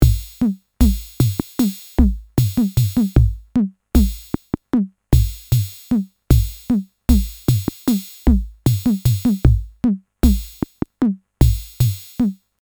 The library contains 16 drum loops from the built-in drum machine of the rare USSR electro-bayan «Orion» in collector’s quality 96 kHz, 32 bit float, with a total volume of 83 MB.